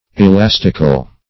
elastical - definition of elastical - synonyms, pronunciation, spelling from Free Dictionary Search Result for " elastical" : The Collaborative International Dictionary of English v.0.48: Elastical \E*las"tic*al\ ([-e]*l[a^]s"t[i^]*kal), a. Elastic.